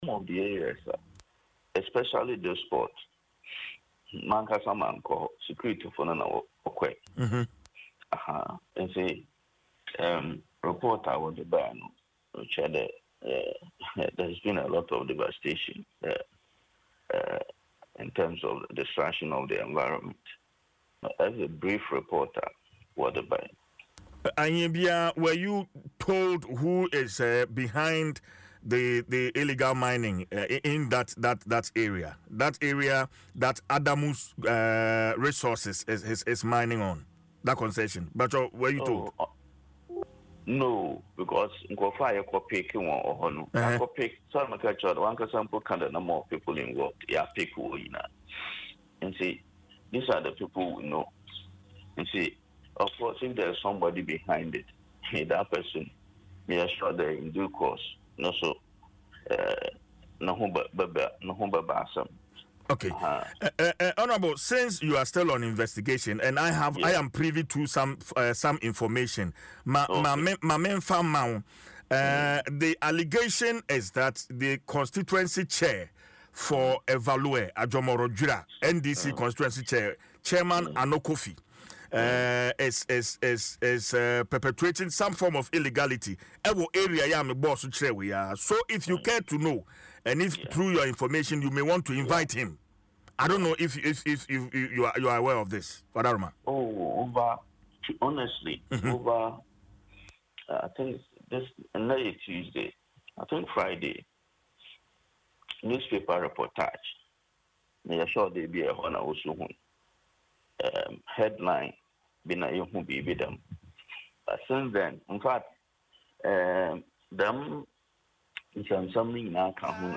Speaking on Adom FM’s morning show, Dwaso Nsem Mr. Nelson stated that his administration is fully committed to the fight against illegal mining and will not shield anyone found culpable.